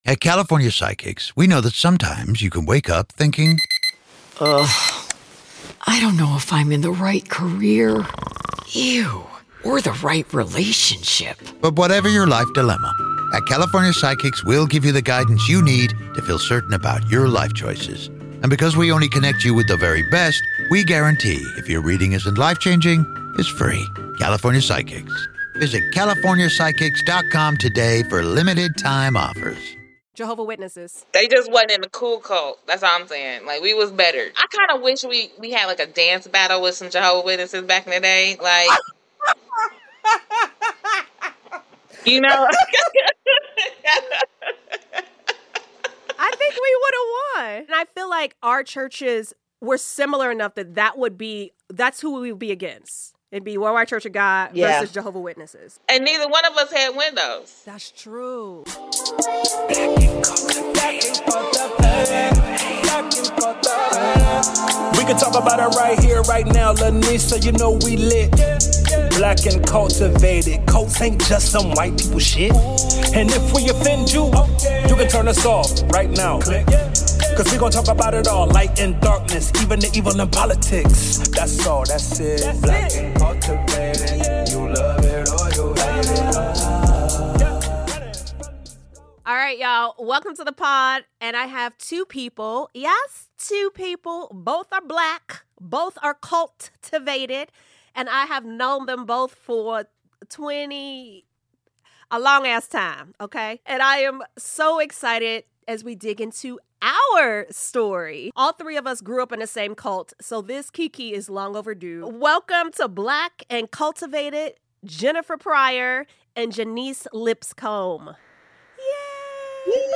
Heads up: There are a couple of little audio hiccups in this one, so bear with us!